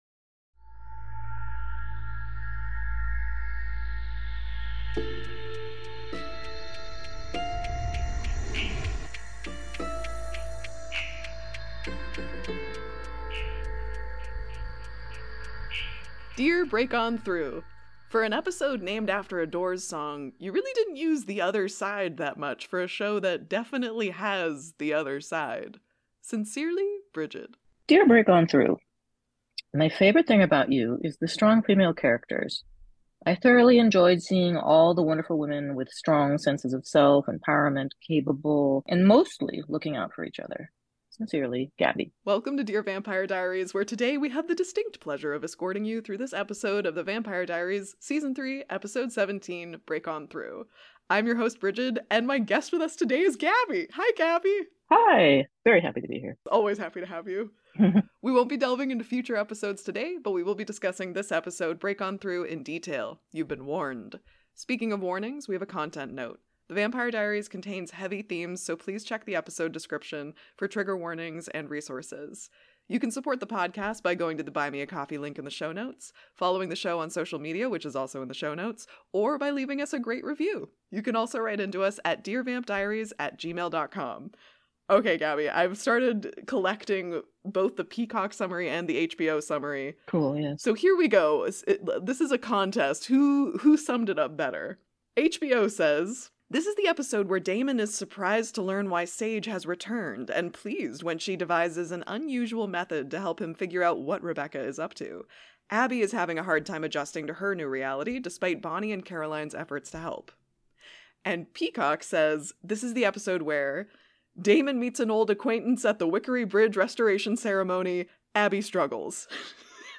Sorry for the intermittent clicking!